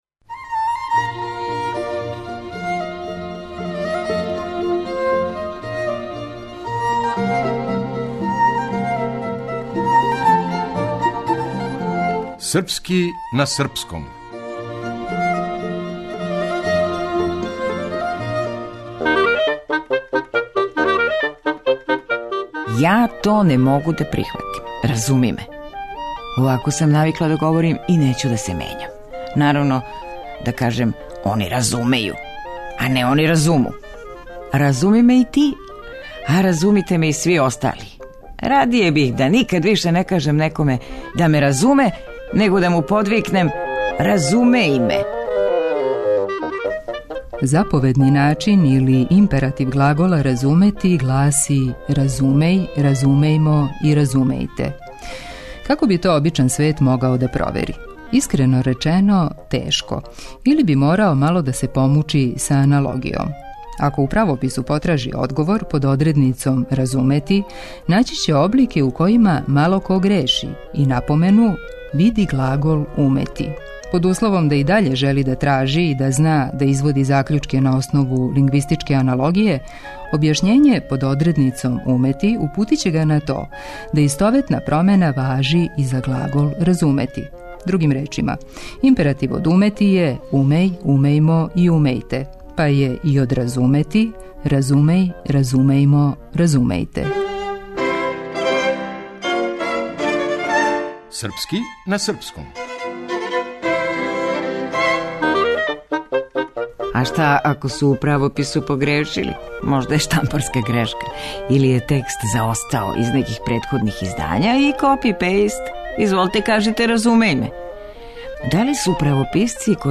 Драмски уметник